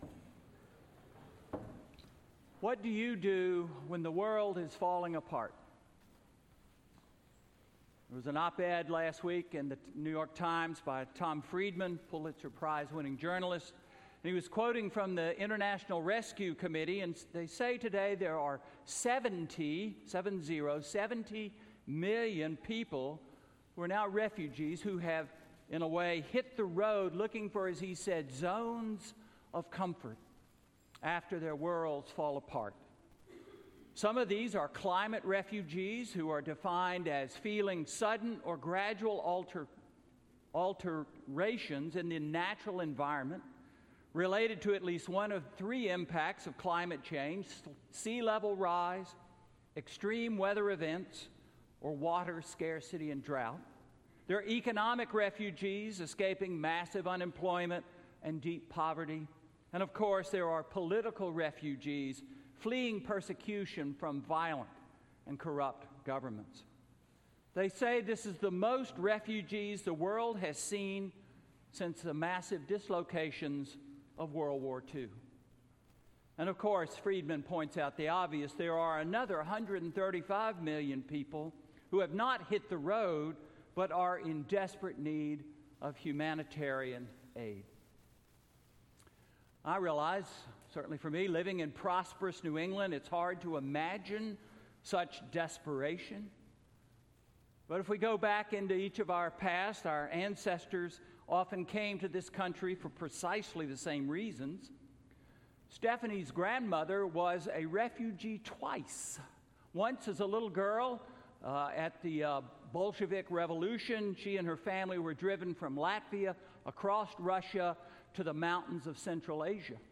Sermon–Hope when the world is falling apart: 12/2/2018